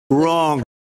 Donald Trump - Wrong Sound Effect MP3 Download Free - Quick Sounds
meme